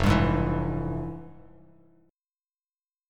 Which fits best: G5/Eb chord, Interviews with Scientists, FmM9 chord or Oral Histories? FmM9 chord